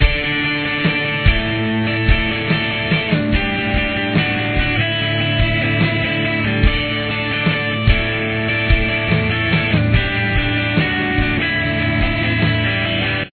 Guitar 2